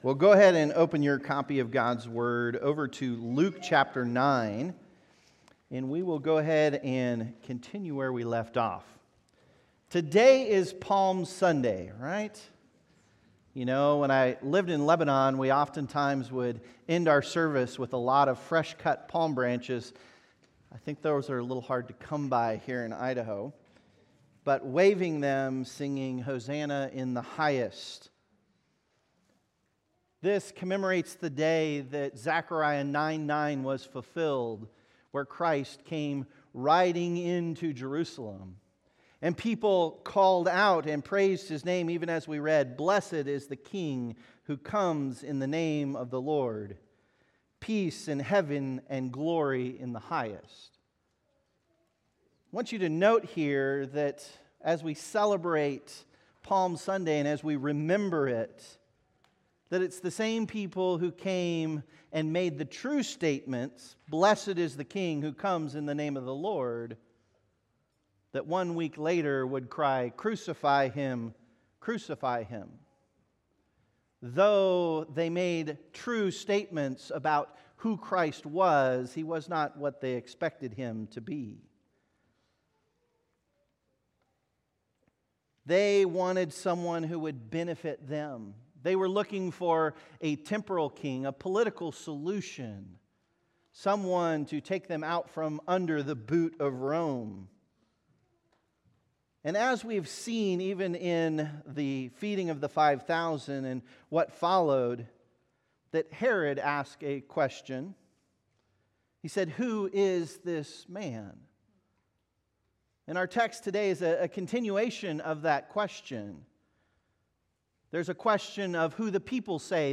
Sermons | New Hope Baptist Church